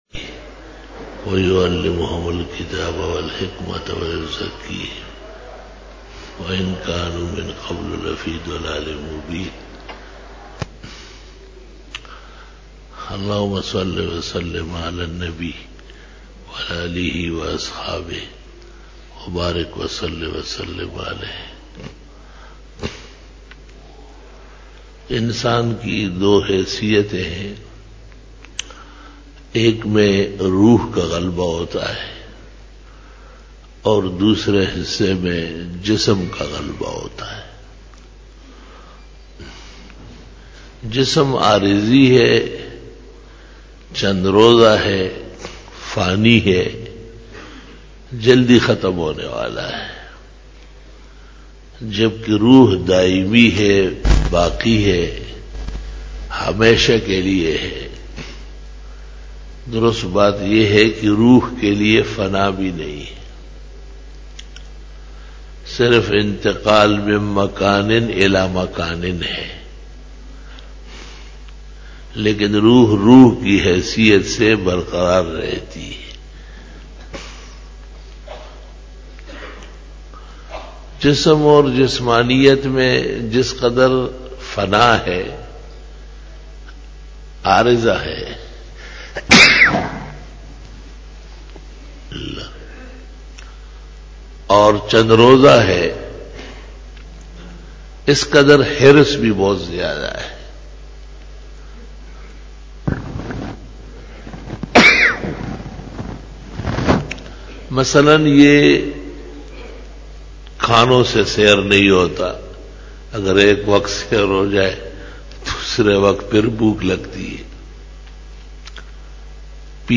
03 Bayan e juma tul mubarak 18-january-2013
Khitab-e-Jummah